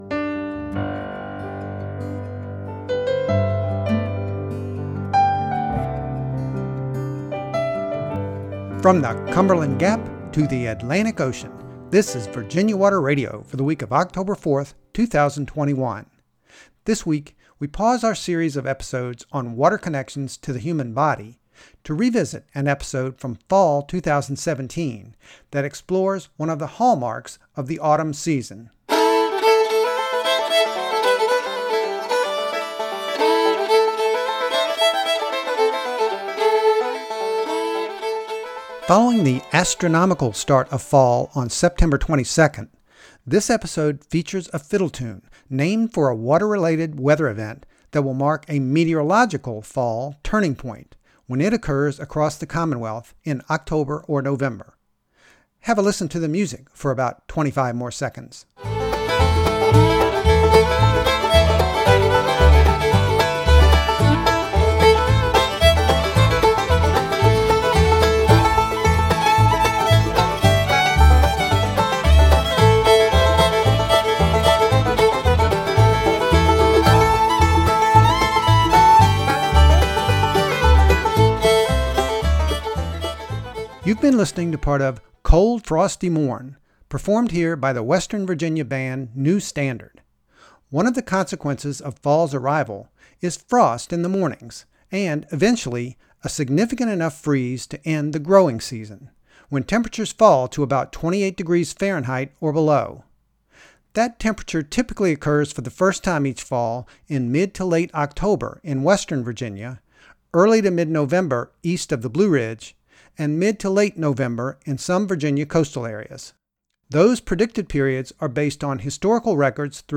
The performance of “Cold Frosty Morn’” heard here is copyright by New Standard, from the 2016 album “Bluegrass,” used with permission.